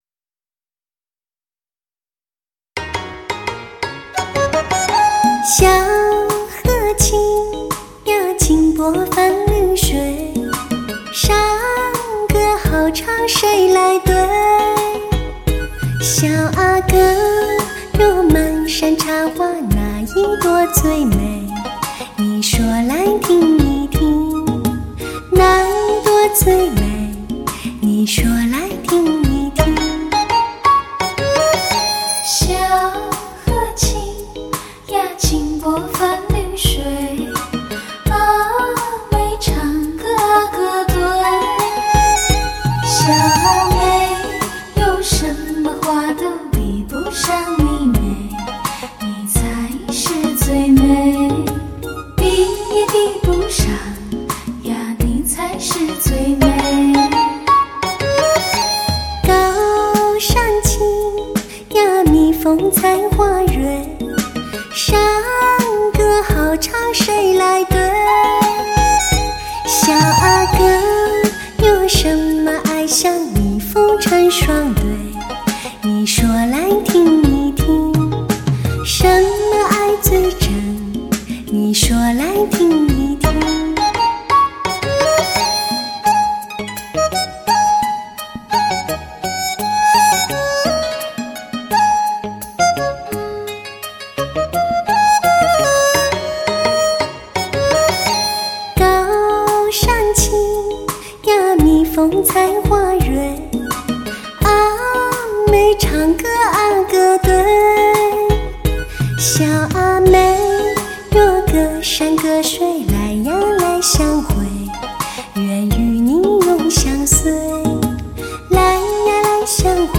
史上人声最甜美 感情最丰富的女声